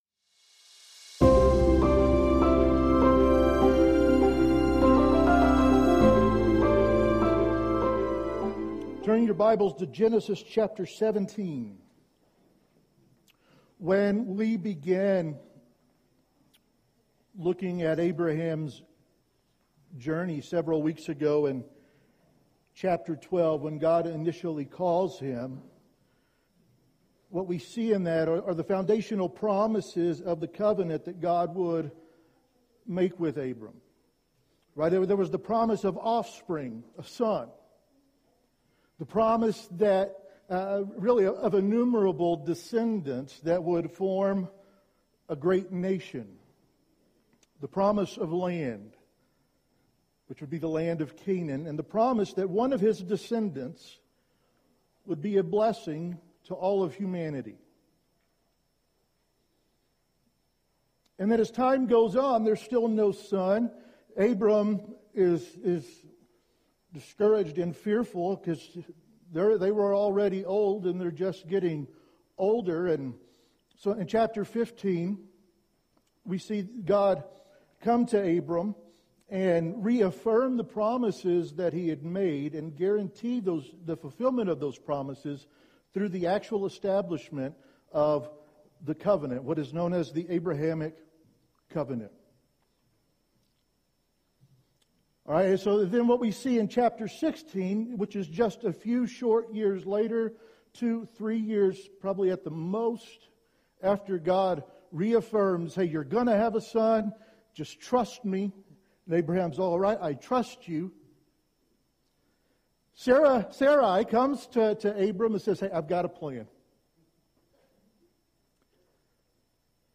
Sermons | Big Horn Baptist Church